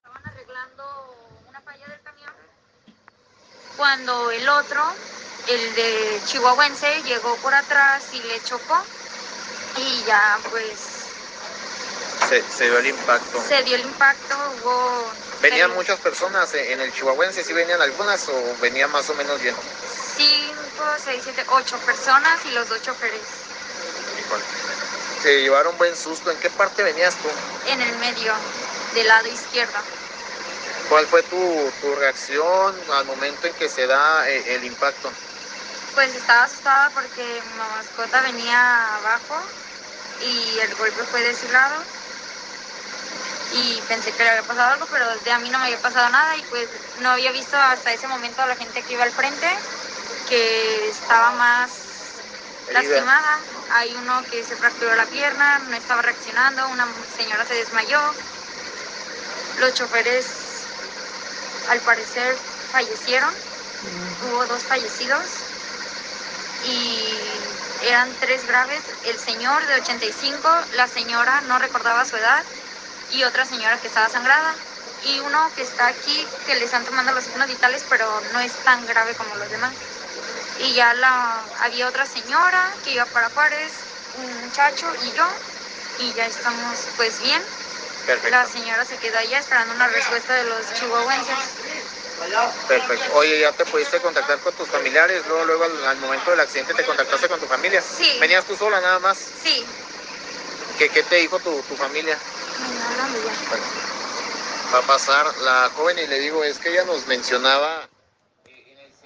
Testimonio de sobreviviente